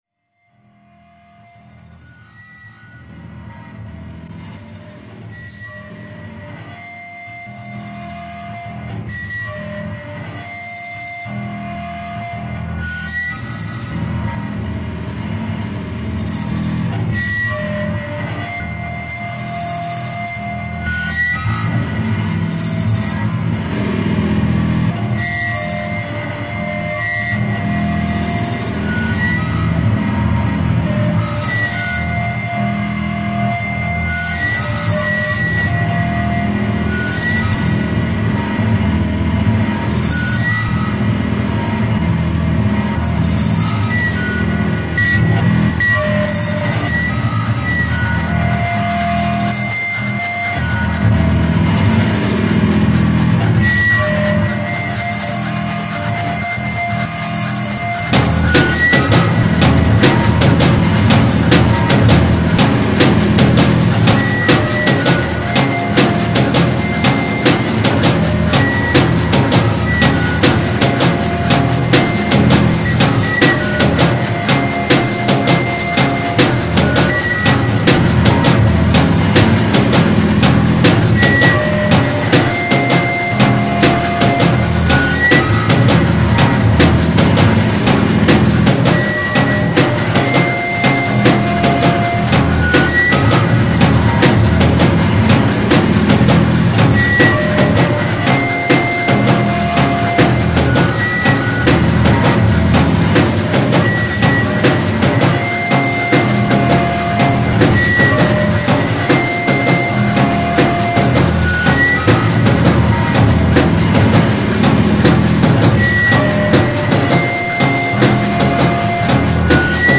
File under: Experimental / Extreme / Tribal Noise Rock